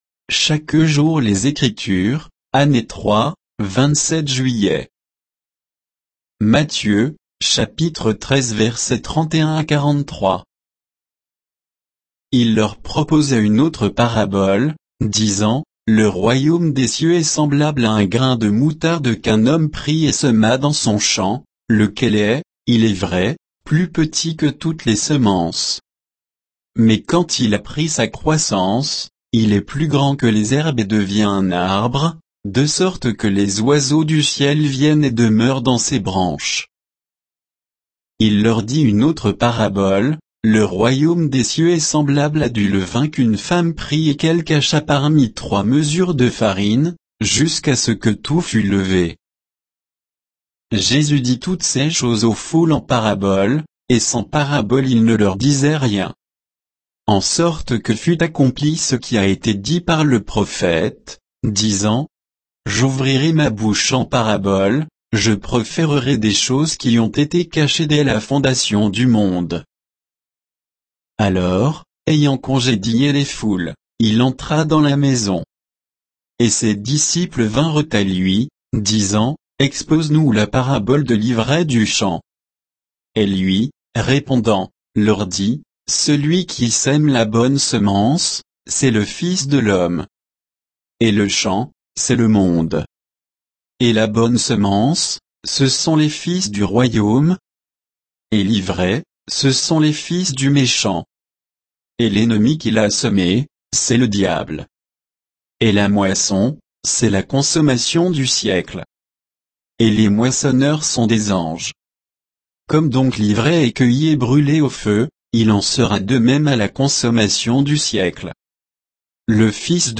Méditation quoditienne de Chaque jour les Écritures sur Matthieu 13, 31 à 43